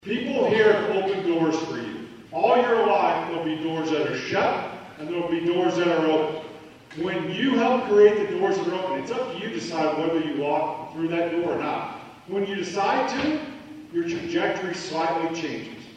Kansas Commissioner of Education Randy Watson gave the keynote address at the ceremony.